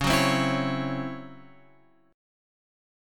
C#mM7bb5 chord {x 4 4 5 5 x} chord